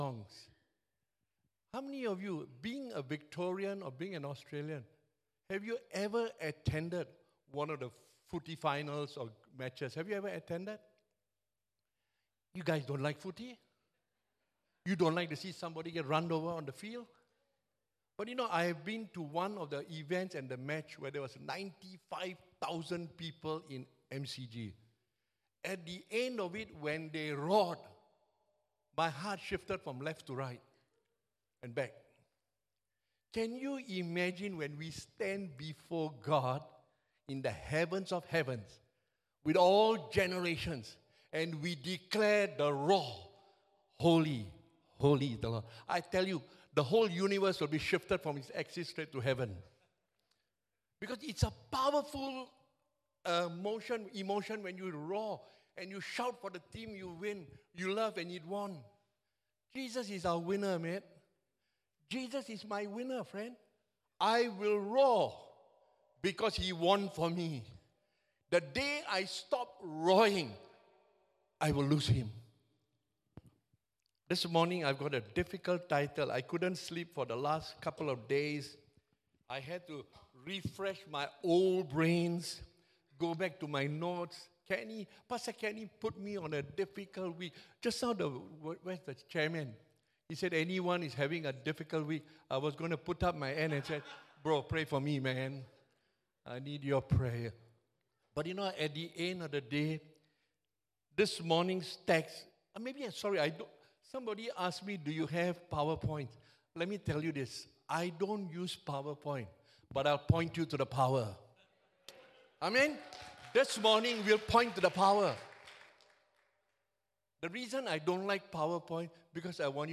English Sermons | Casey Life International Church (CLIC)
English Worship Service - 17 September 2023